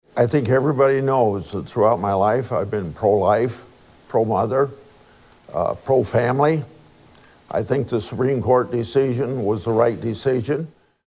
The debate, which aired on Iowa PBS October 6th., is the only joint appearance the candidates will make before the November election.